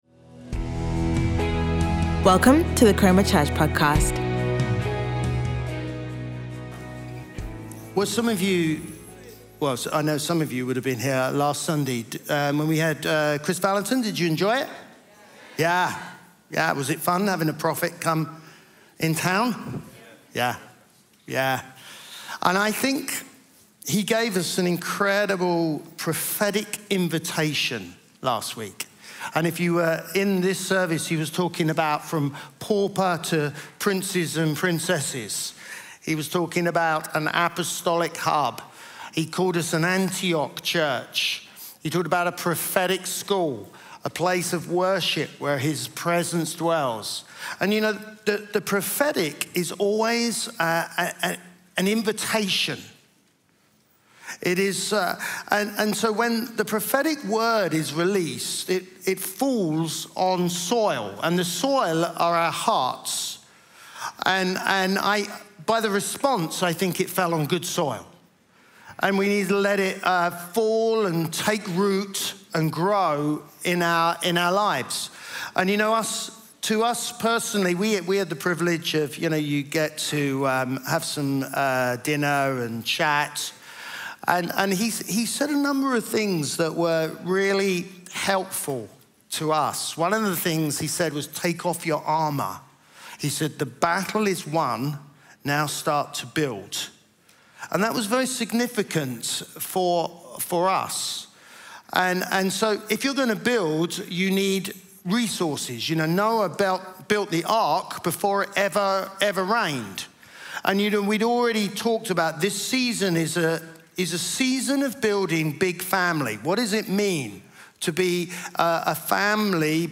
Chroma Church - Sunday Sermon Money